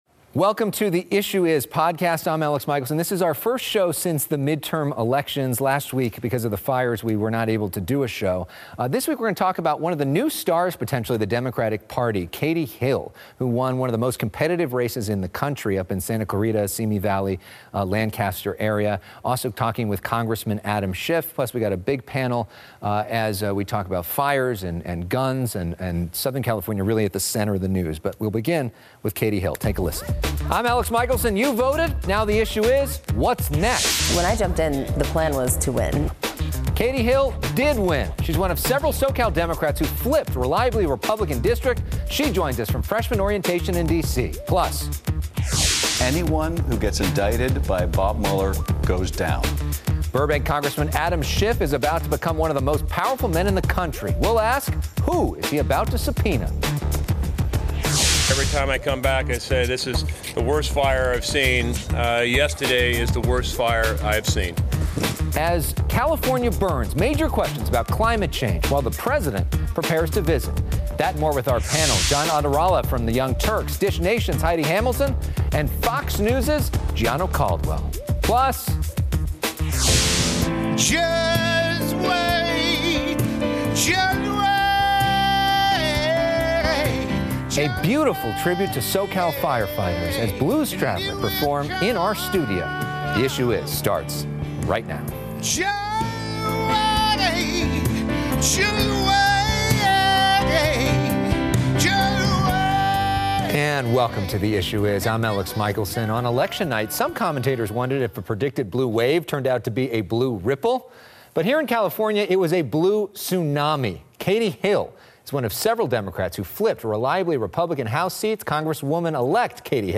Congresswoman-Elect Katie Hill joins us from freshman orientation on Capitol Hill.